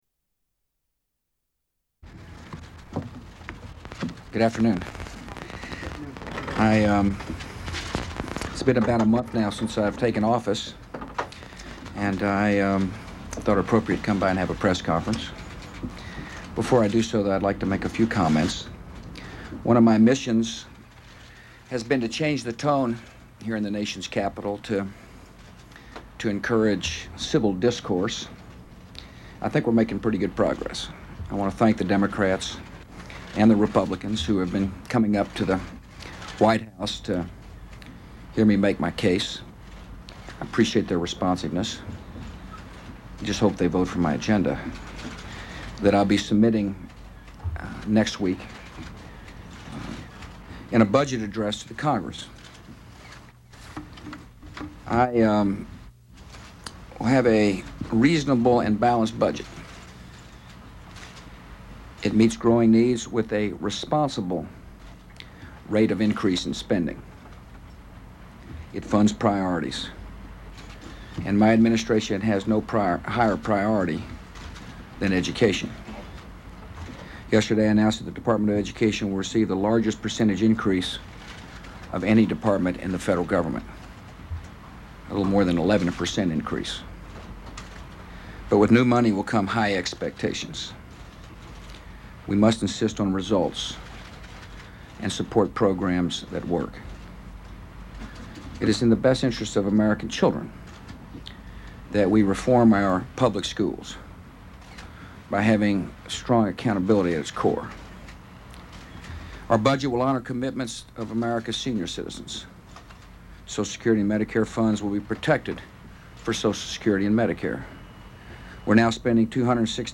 U.S. President George W. Bush's first official presidential news conference